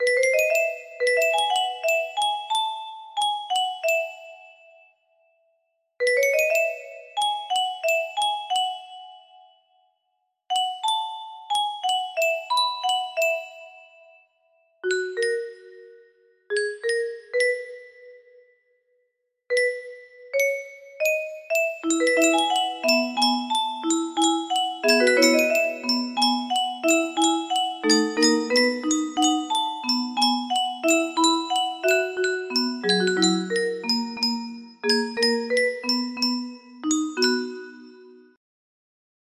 May plants regrow? music box melody
Full range 60